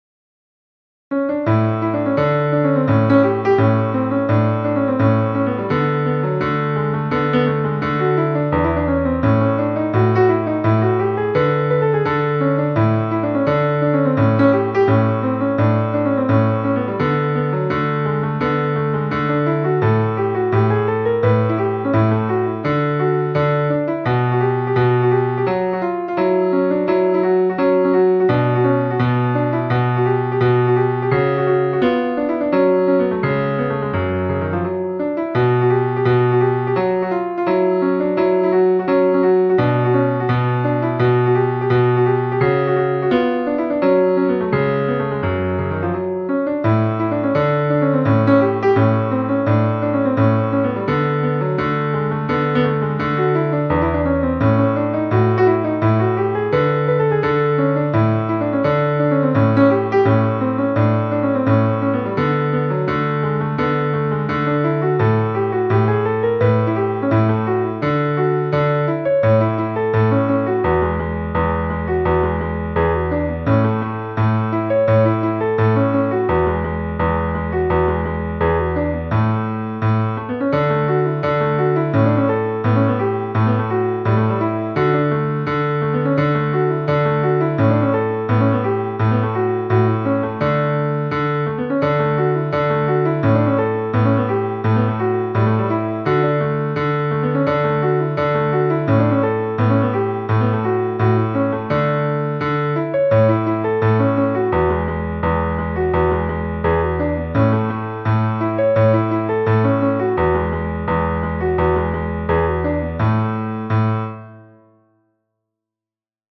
In effetti, la sua musica è scorrevole e piacevolissima e ve la consiglio per esercitazioni senza soverchie difficoltà.